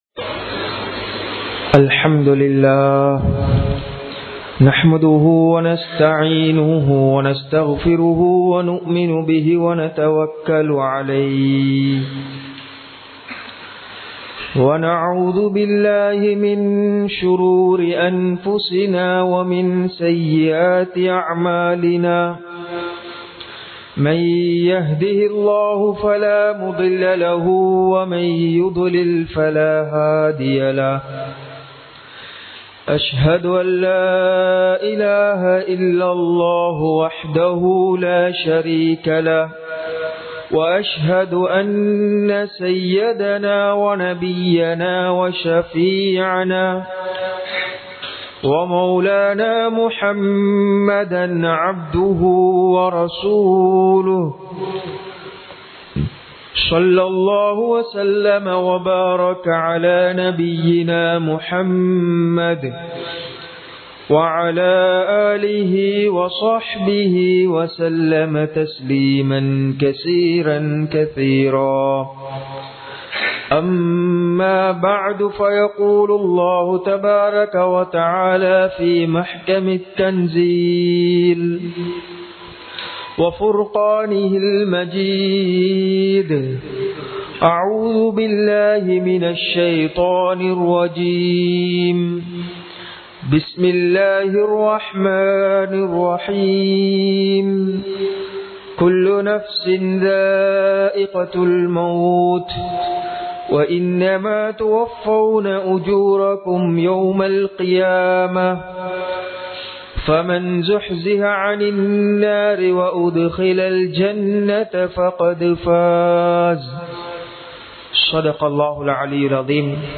மறுமைக்கான வருமானம் | Audio Bayans | All Ceylon Muslim Youth Community | Addalaichenai
Muhiyadeen Jumua Masjith